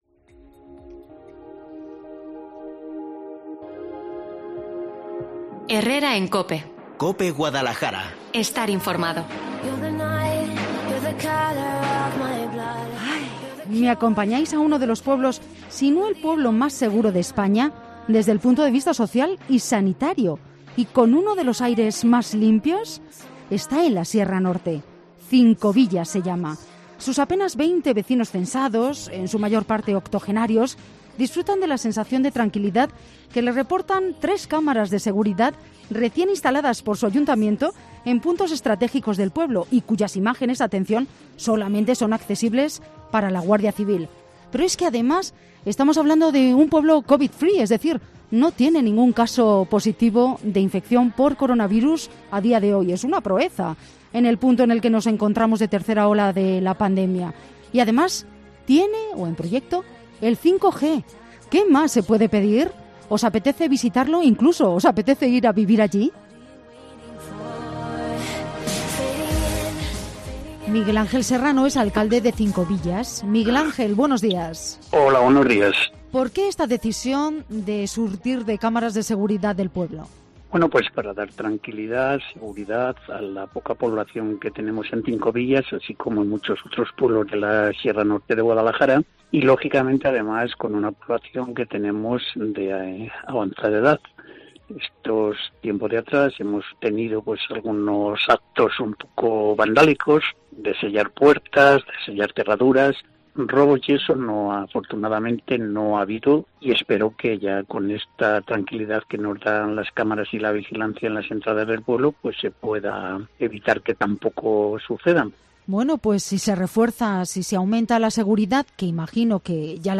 Una medida que, según nos ha explicado el alcalde, Miguel Ángel Serrano, tiene como objetivo "dar seguridad, tranquilidad a la poca población que tenemos en Cincovillas, como ocurre también en muchos otros pueblos de la Sierra Norte de Guadalajara, que además es de avanzada edad".